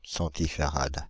Ääntäminen
France (Île-de-France): IPA: /sɑ̃.ti.fa.ʁad/